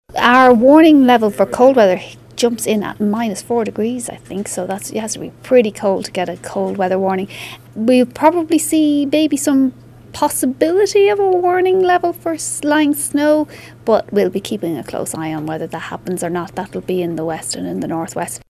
Meteorologist Joanna Donnelly hasn’t ruled out a weather warning being issued: